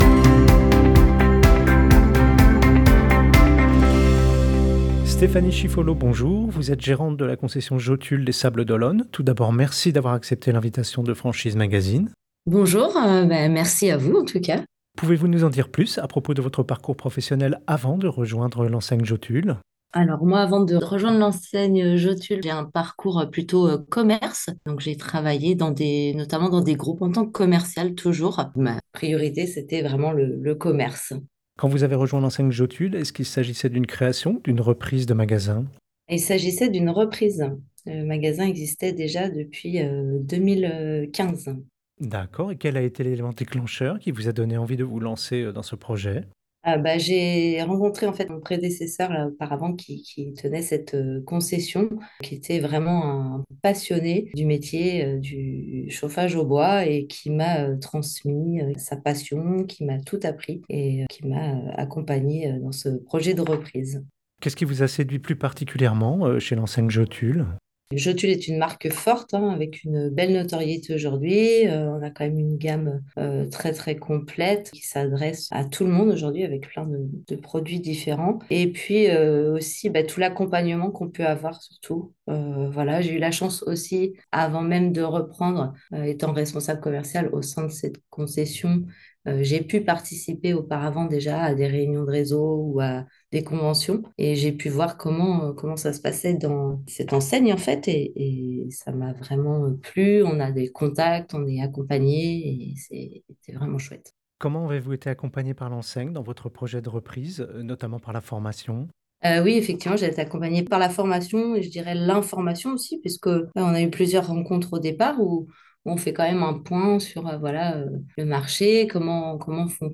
Interviews des réseaux